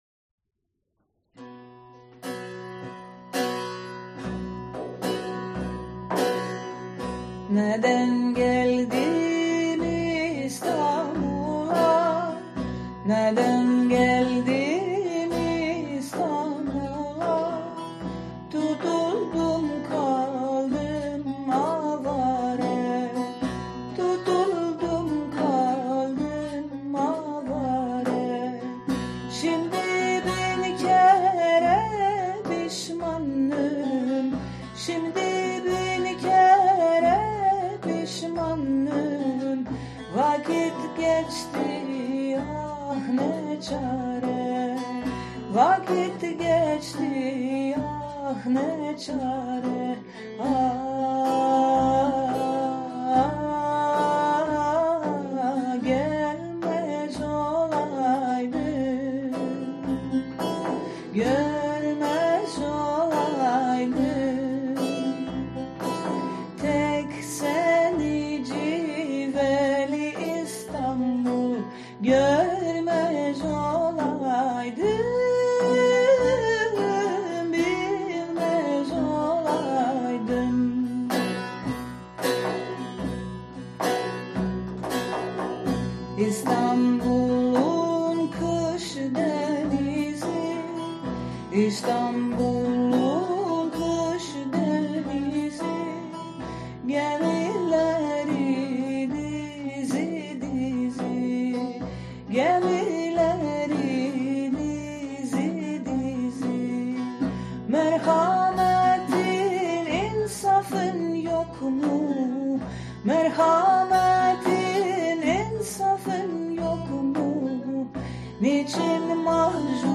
saz